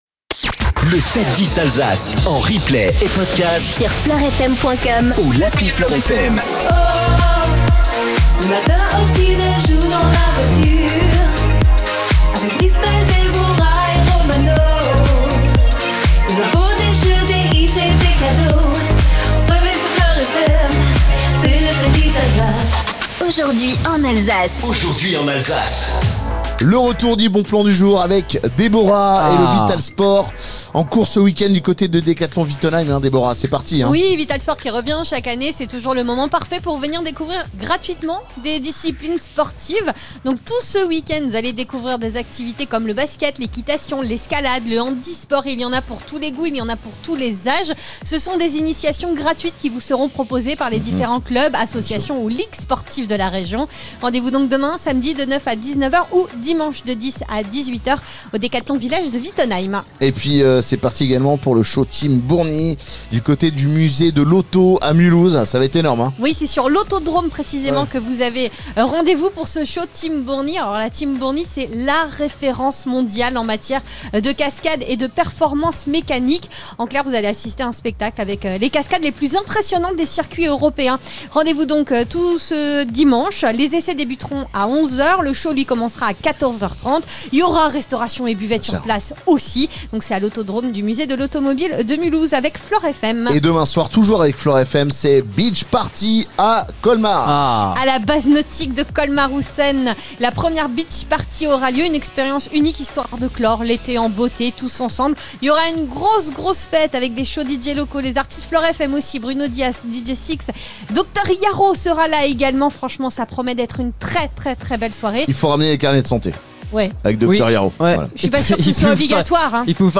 710 ALSACE FLOR FM PODCAST MORNING ALSACE COTE PAYSAN CREDIT MUTUEL ENTREPRISES LK FLOR FM Vendredi 29 août. 0:00 26 min 37 sec 29 août 2025 - 26 min 37 sec LE 7-10 DU 29 AOÜT Retrouvez les meilleurs moments du 7-10 Alsace Tour 2025, ce jeudi 28 août dans les rues de Cernay/ Guebwiller.